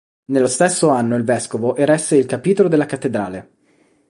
ca‧pì‧to‧lo
Pronunciado como (IPA)
/kaˈpi.to.lo/